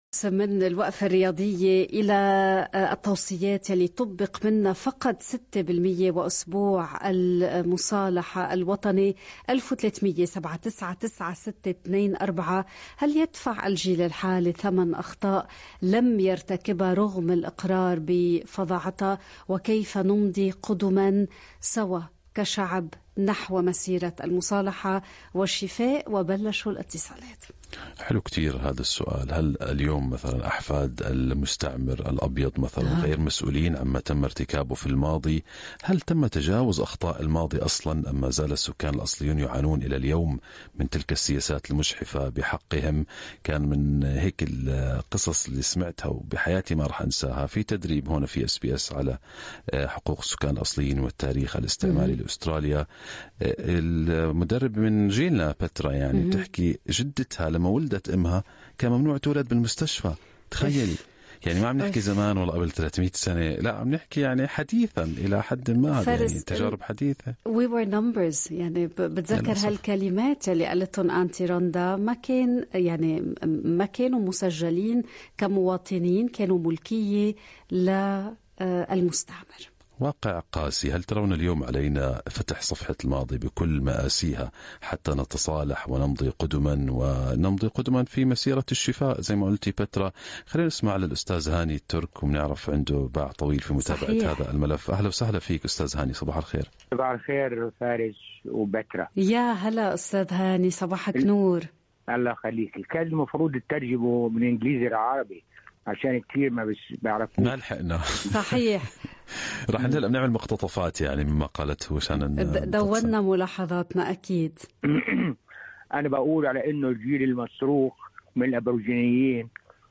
في إطار أسبوع المصالحة، استمعنا إلى آراء أبناء الجالية العربية حول أهمية الاعتراف بتاريخ الشعوب الأصلية في أستراليا، ومكانة هذا الأسبوع في تعزيز الحوار والتفاهم المشترك. تباينت الآراء بين من يرى فيه فرصة للتقارب وبناء جسور، وآخرين يطالبون بخطوات عملية لتحقيق العدالة الحقيقية.